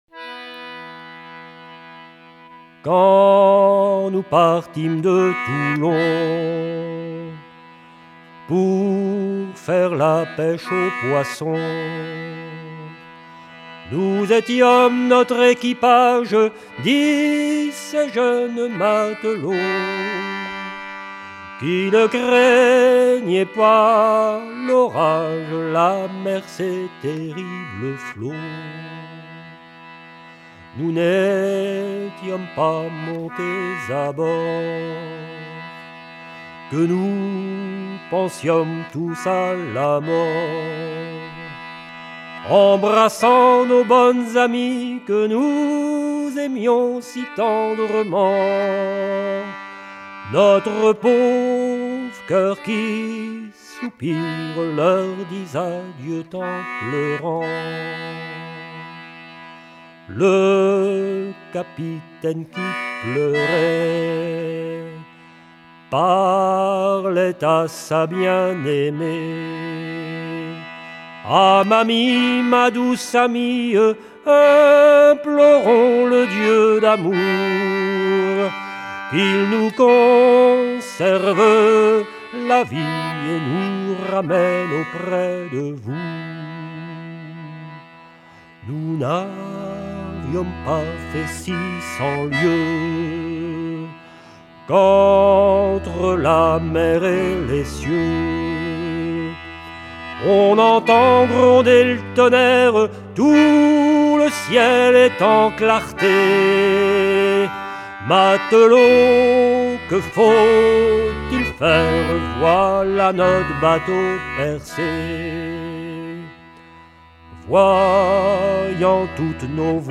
Version recueillie en 1978
Genre strophique
Pièce musicale éditée